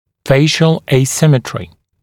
[‘feɪʃl eɪ’sɪmətrɪ][‘фэйшл эй’симэтри]асимметрия лица